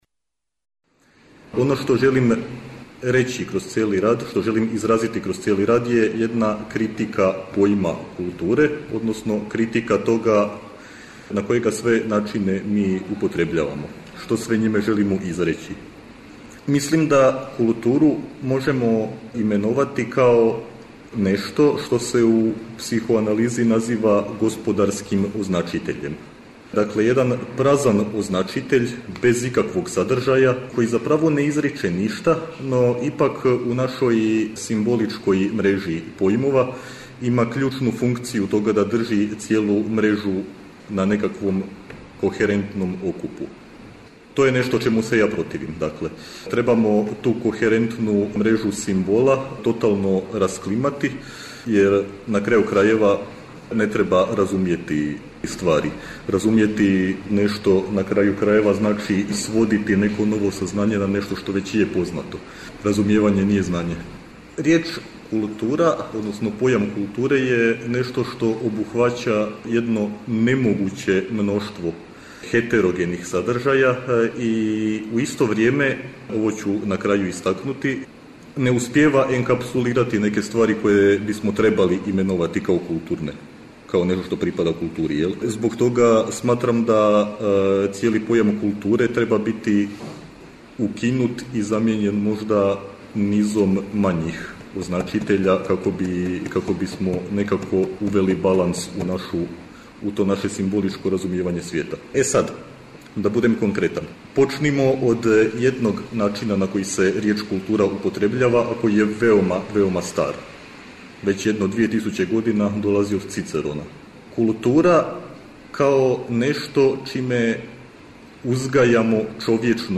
У циклусу НАУКА И САВРЕМЕНИ УНИВЕРЗИТЕТ четвртком смо емитовали снимке са истоименог научног скупа, који је одржан 11. и 12. новембра на Универзитету у Нишу.
Научни скупови